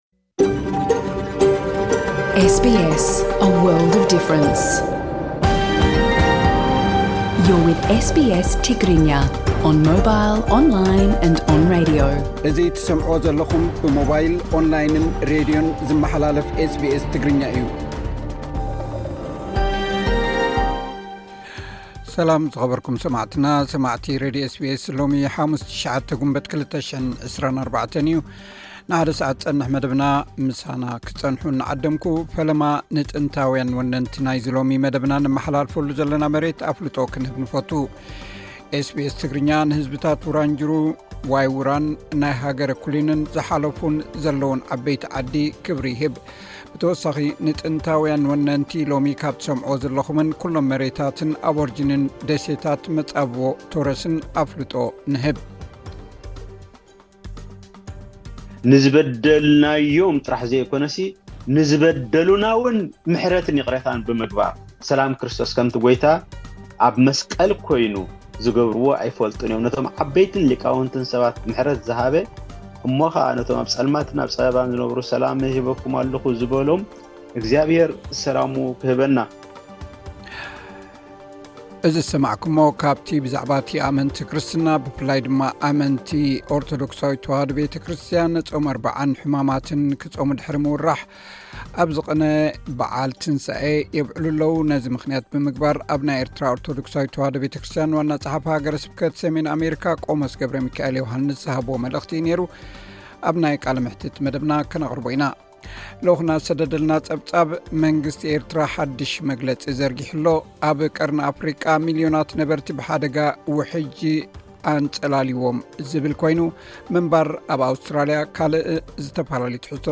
ዜናታት ኤስ ቢ ኤስ ትግርኛ (09 ግንቦት 2024)